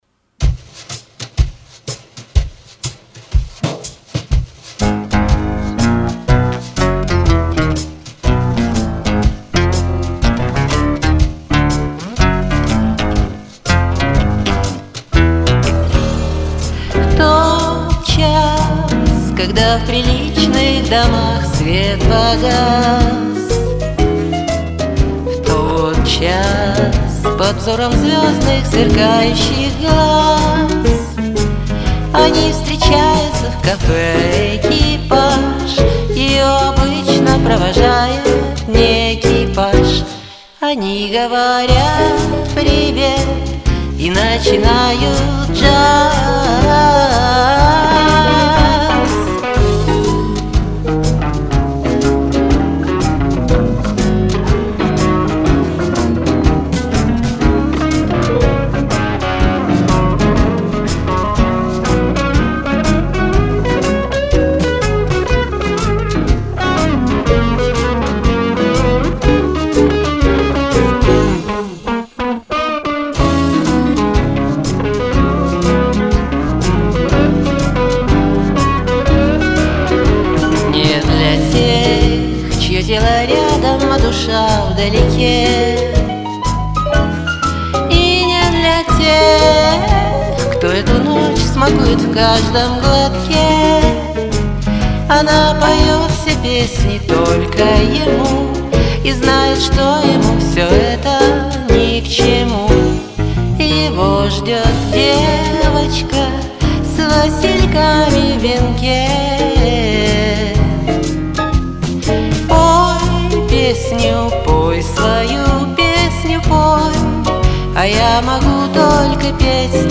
Здорово спели обе участницы!
Очень хорошо спели обе участницы.